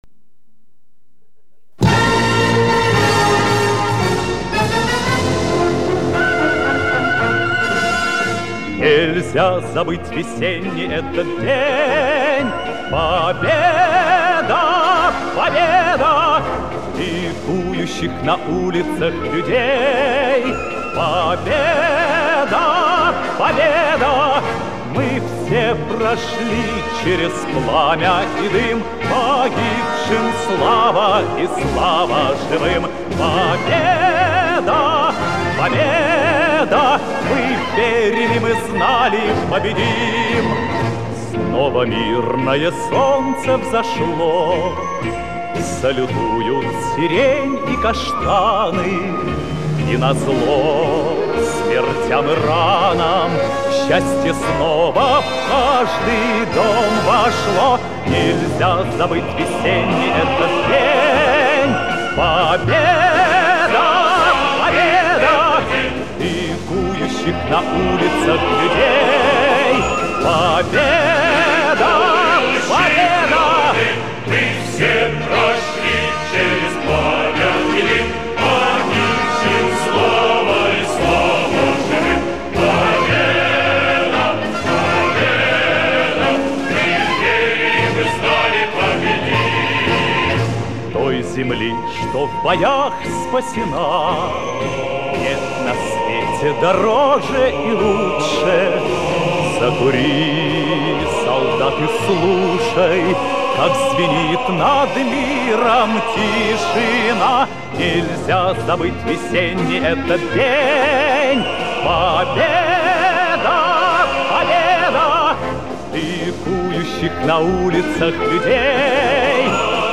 • Файлы: mp3, минус,
• Жанр: Детские песни
патриотическая